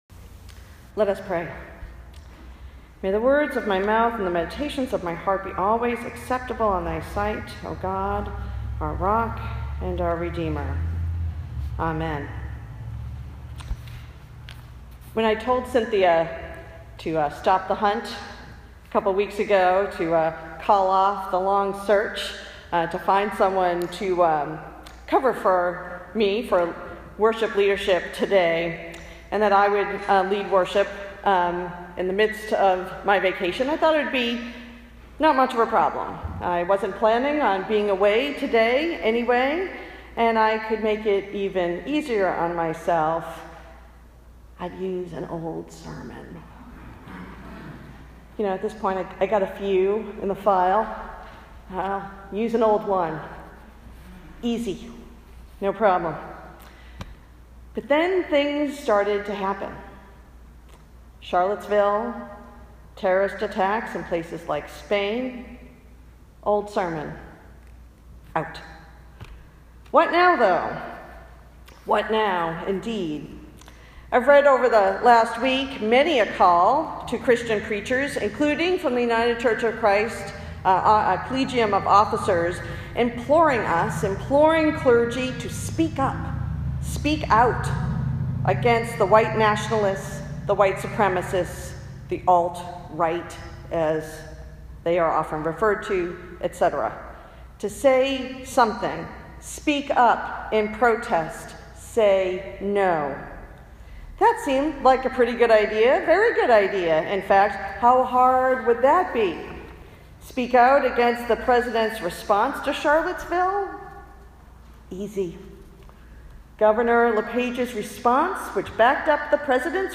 Sermon August 20, 2017 – Old South Congregational Church, United Church of Christ